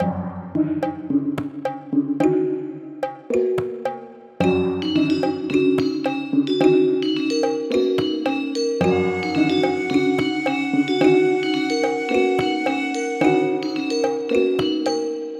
東南アジアのガムランという音楽の要素を取り入れたBGMです！
ループ：◎
BPM：109 キー：F#ペンタトニック ジャンル：ゆったり 楽器：ファンタジー、ストリングス